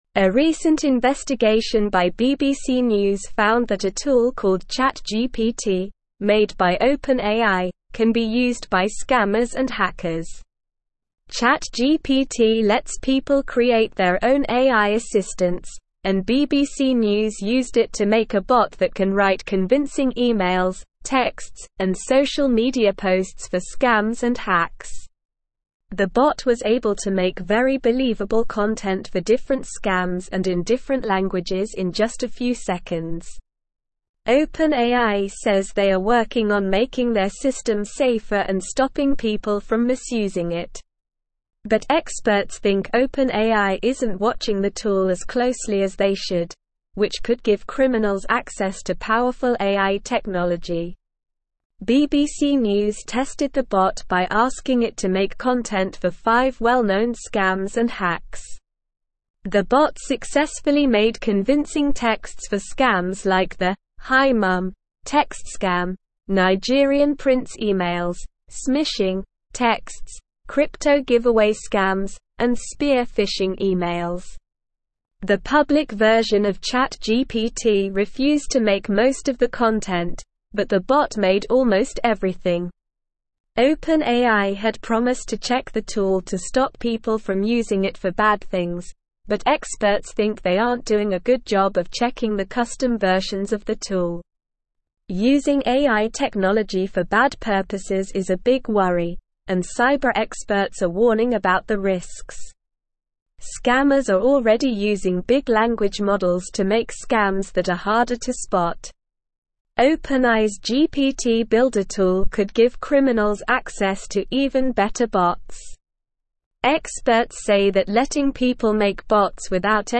Slow
English-Newsroom-Upper-Intermediate-SLOW-Reading-OpenAIs-ChatGPT-Tool-Raises-Concerns-About-Cybercrime.mp3